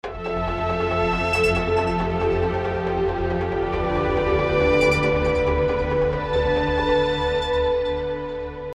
BPM: 80
Стиль: Попсовый медляк